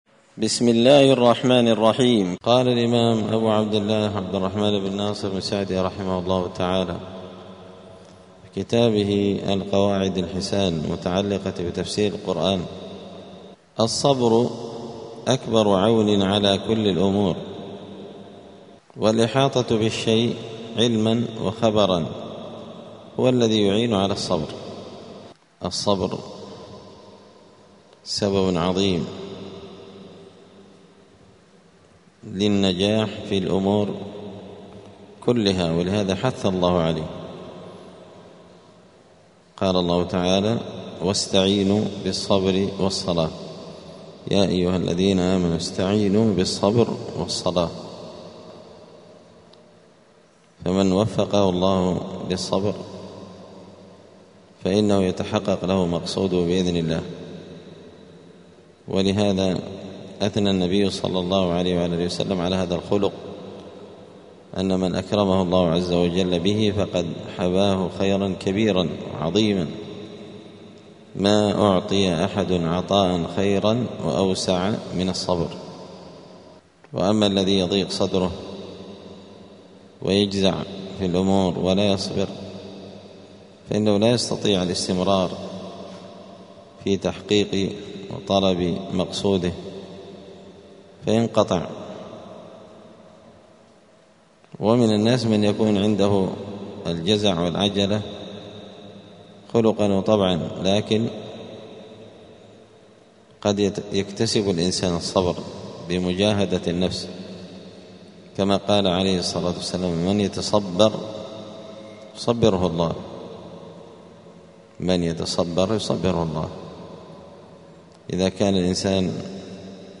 *الدرس الثاني والسبعون (72) : القاعدة الثانية والستون اﻟﺼﺒﺮ ﺃﻛﺒﺮ ﻋﻮﻥ ﻋﻠﻰ ﺟﻤﻴﻊ اﻷﻣﻮﺭ.*
دار الحديث السلفية بمسجد الفرقان قشن المهرة اليمن